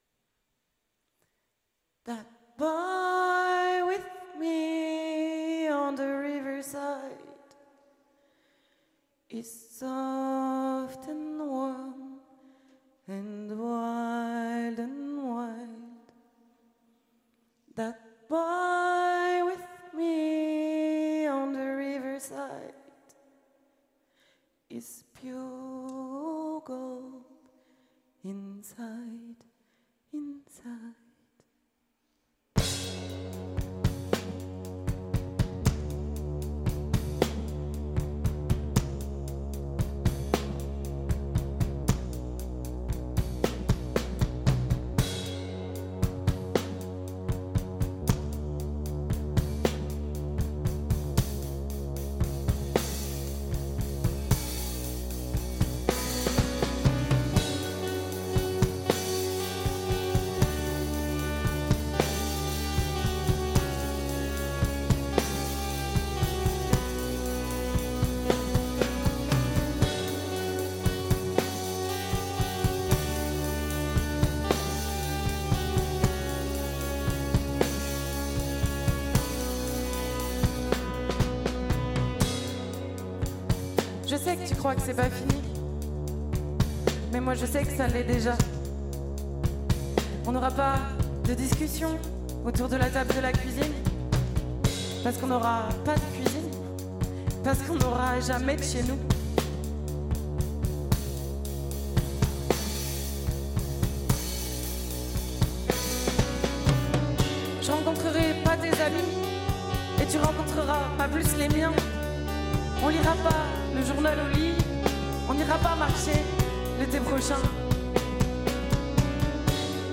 À l’occasion des INOUÏS du Printemps de Bourges 2024, les radios de la FRAP ont interviewé le groupe nantais Ile de Garde.